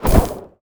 fireball_projectile_deflect_01.wav